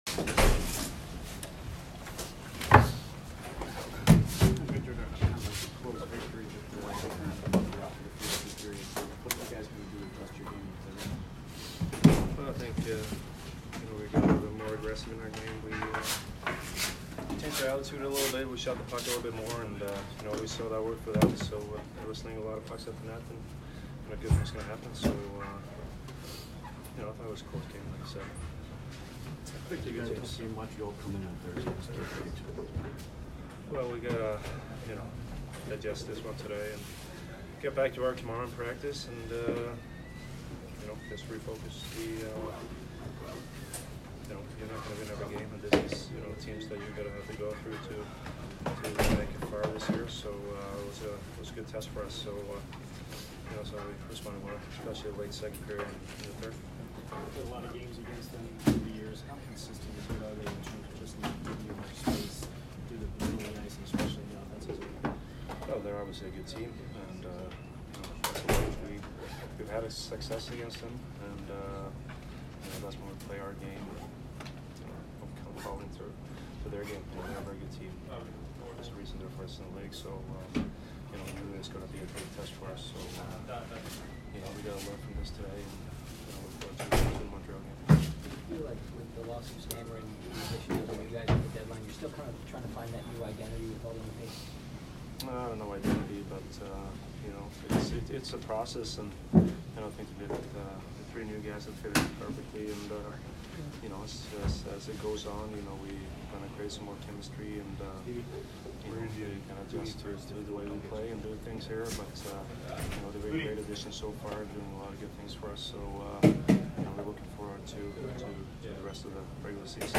Victor Hedman post-game 3/3